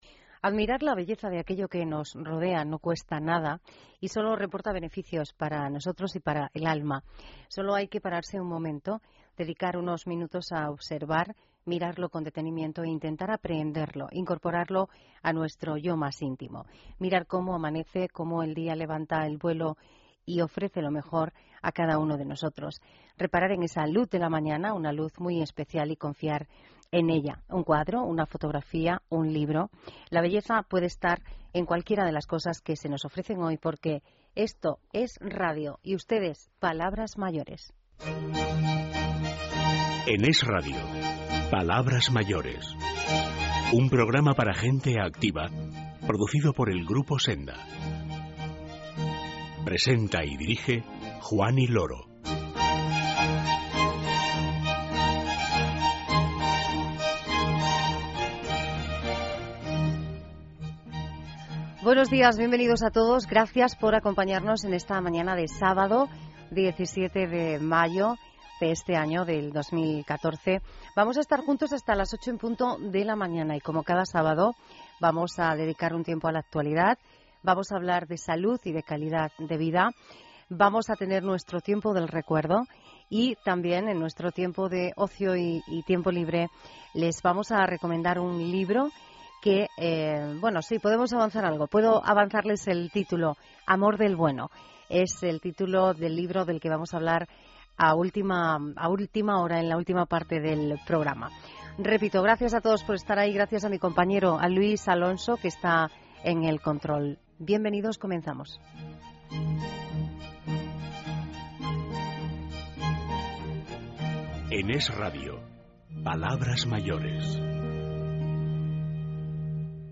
Radio: Programa «Palabras Mayores» de EsRadio (17 Mayo 2014). Podcast